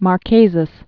(mär-kāzəz, -səz, -səs)